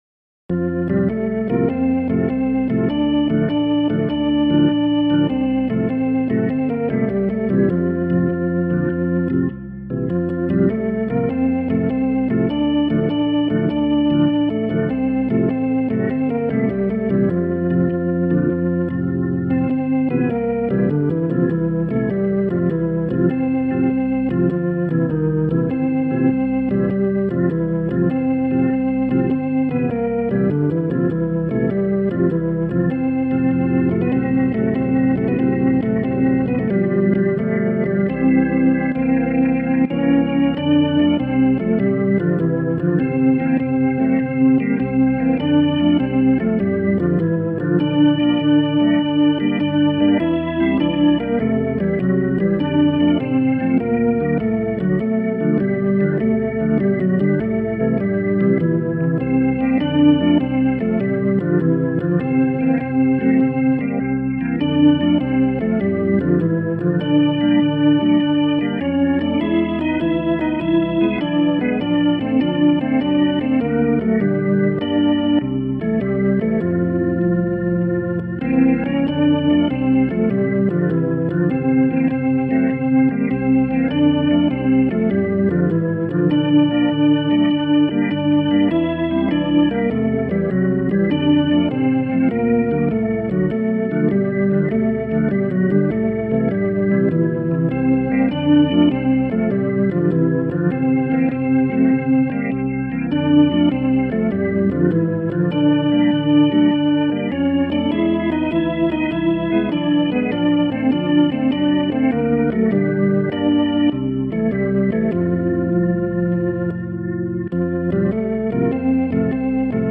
Music Hall Songs: